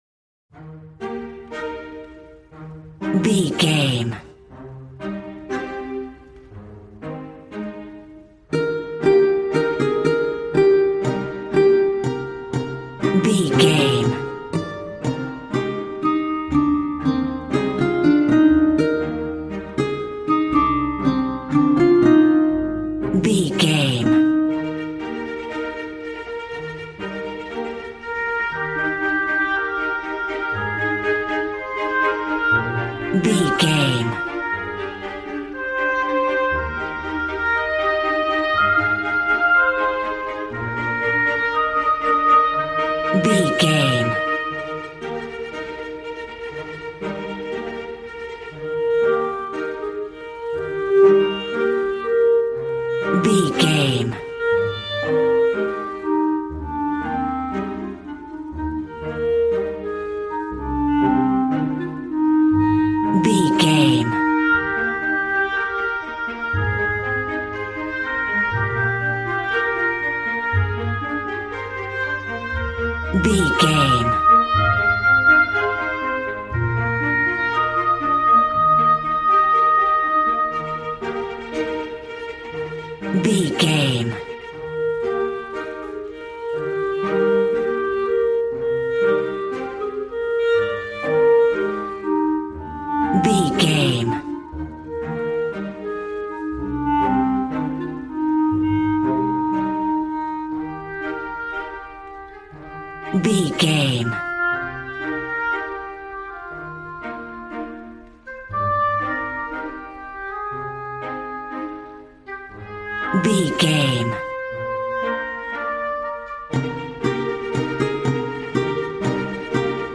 Regal and romantic, a classy piece of classical music.
Ionian/Major
E♭
regal
piano
violin
strings